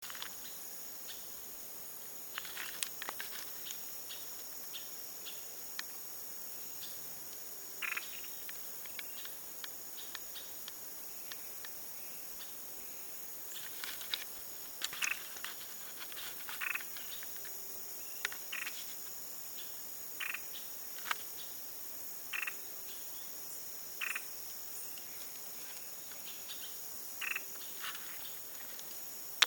Poecilotriccus plumbeiceps (Lafresnaye, 1846)
Nome em Inglês: Ochre-faced Tody-Flycatcher
Ouça o canto curioso desta ave, clicando em ouvir